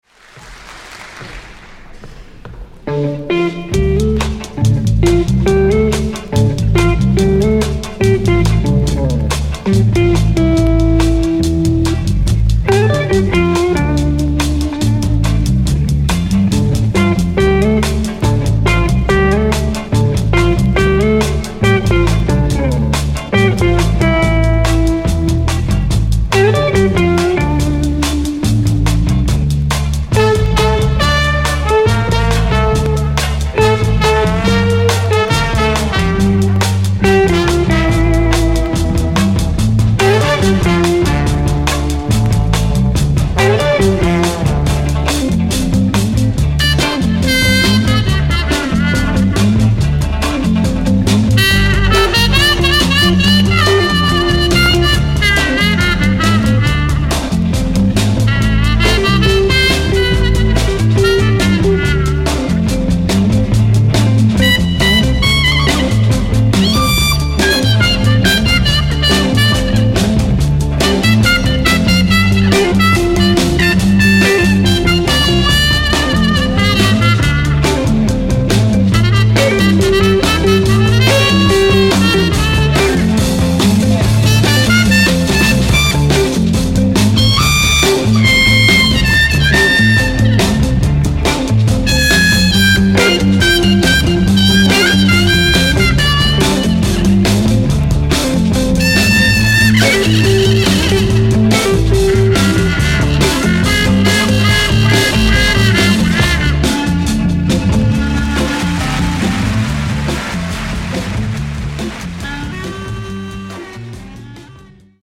1枚目がスタジオ録音、2枚目がライブ録音盤になっています。